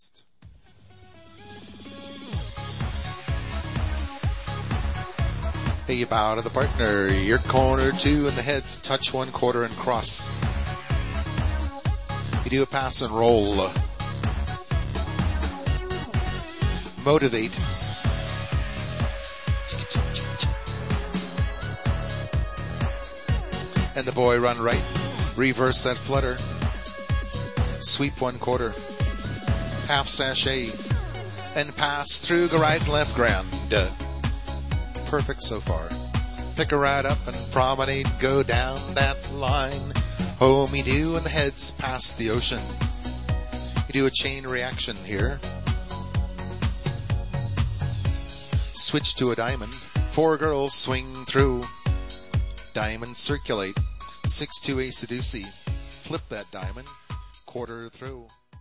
Category: Patter